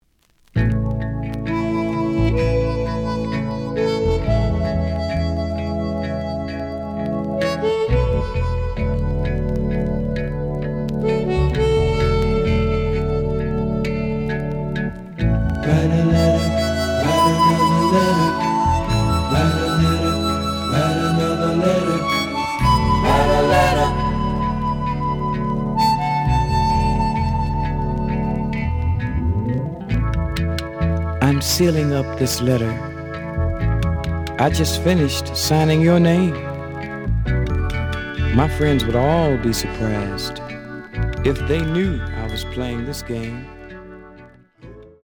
The audio sample is recorded from the actual item.
●Format: 7 inch
●Genre: Soul, 70's Soul
Slight edge warp.